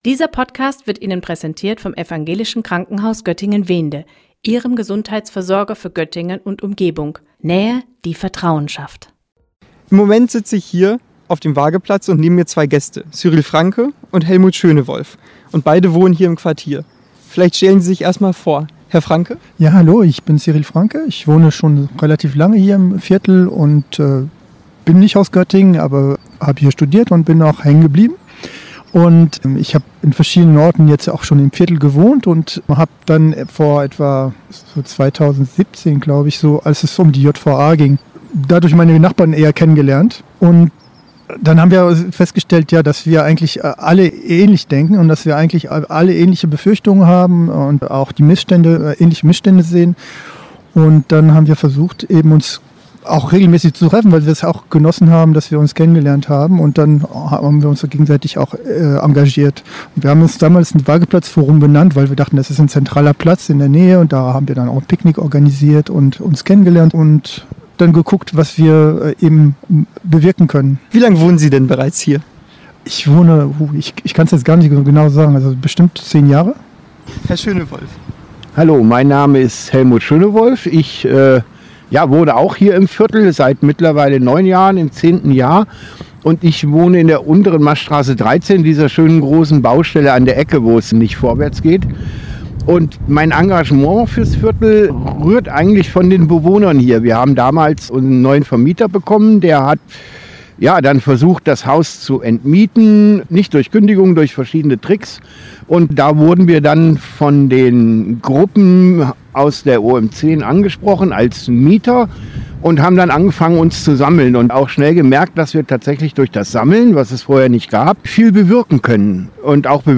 Im Gespräch: Wohnen im ‚Sanierungsgebiet nördliche Innenstadt’